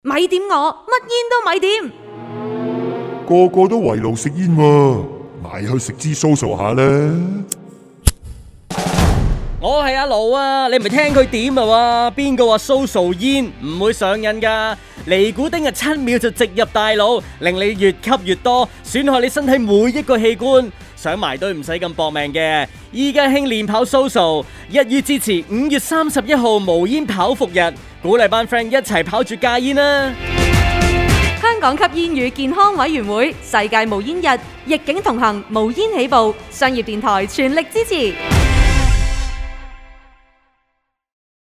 Listen to the radio appeal of Alex FONG